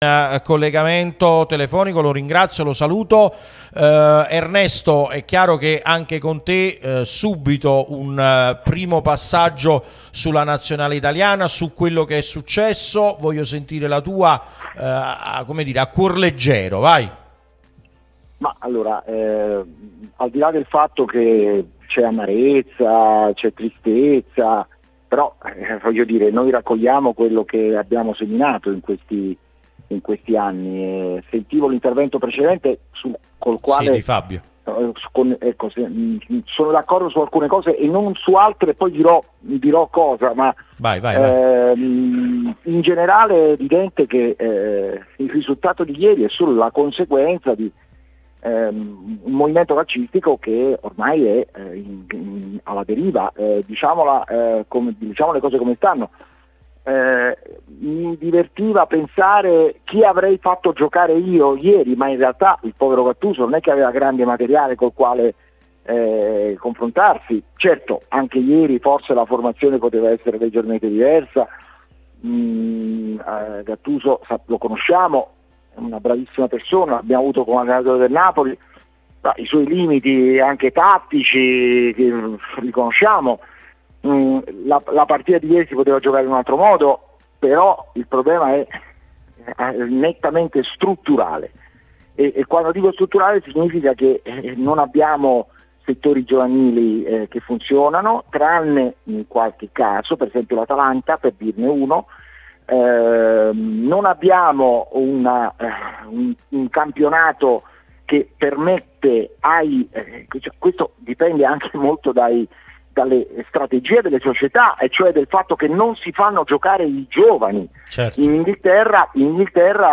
Radio Tutto Napoli: l’intervistato analizza crisi Italia, giovani e Lukaku — “movimento alla deriva”, serve riforma.
Ascolta l'audio Ernesto Caccavale , giornalista ed ex europarlamentare, è intervenuto nel corso di 'Difendo la Città', trasmissione sulla nostra Radio Tutto Napoli , prima radio tematica sul Napoli, in onda tutto il giorno, che puoi ascoltare/vedere qui sul sito o sulle app ( qui per Iphone/Ipad o qui per Android ).